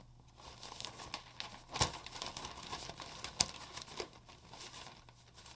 塑料包装袋
描述：摆弄塑料包装袋沙沙声
Tag: 塑料 沙沙 ASMR